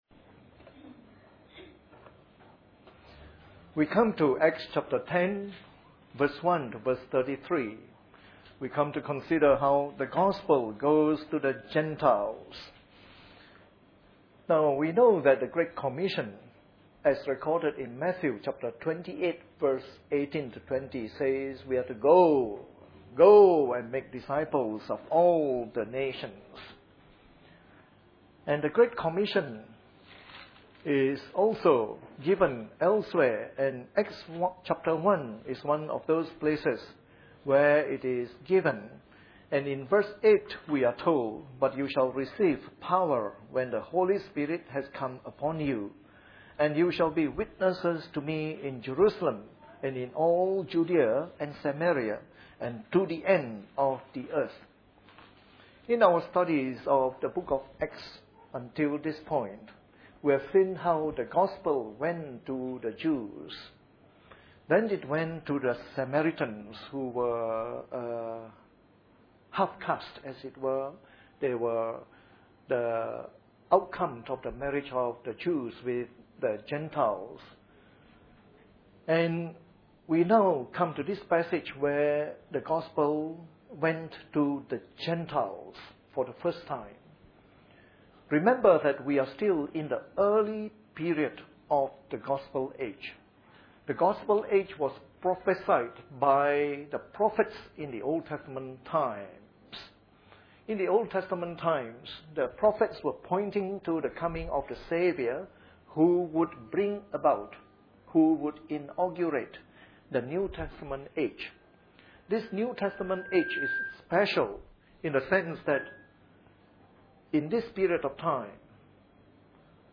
Part of our series on “The Acts of the Apostles” delivered in the Evening Service.